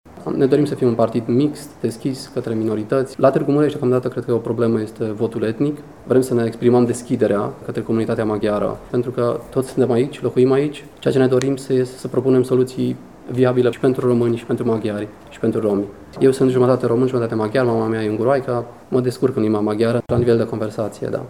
Noua organizație își propune să fie un partid mixt, deschis minorităților, a declarat astăzi în prima conferință de presă